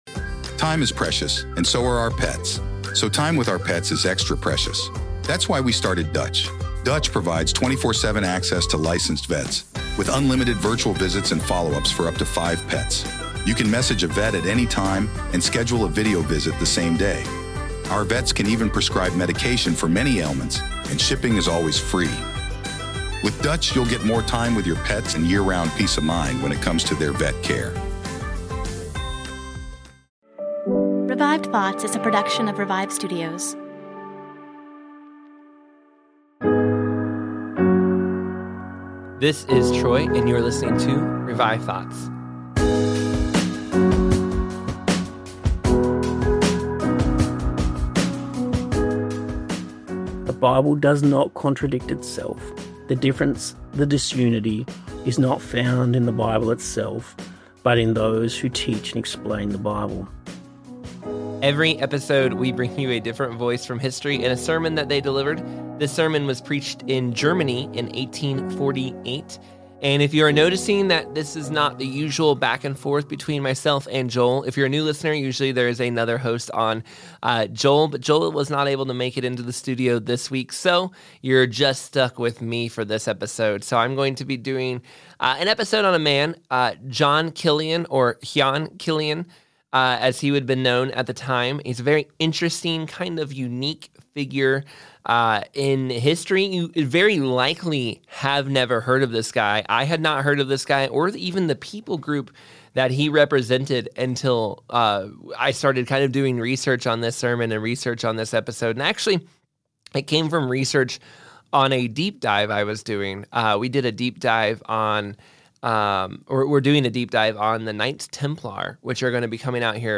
We are bringing history's greatest sermons back to life!